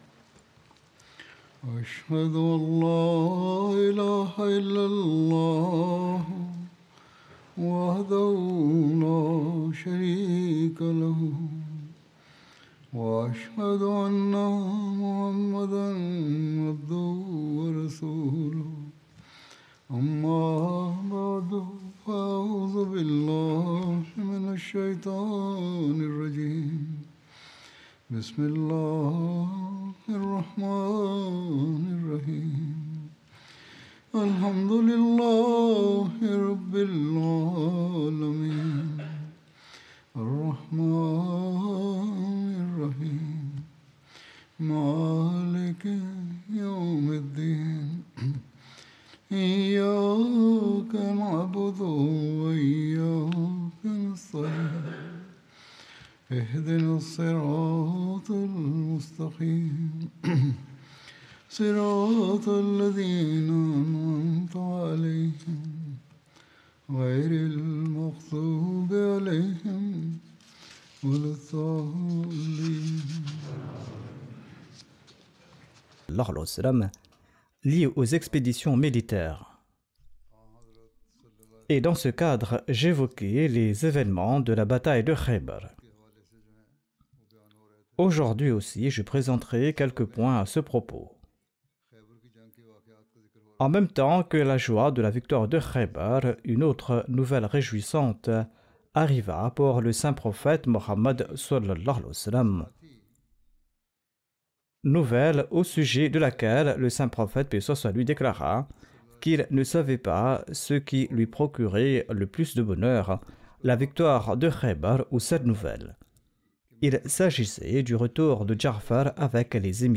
French Translation of Friday Sermon delivered by Khalifatul Masih